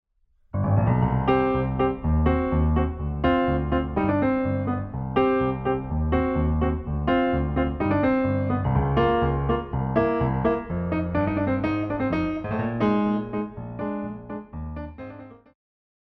古典,流行
钢琴
演奏曲
世界音乐
仅伴奏
没有主奏
没有节拍器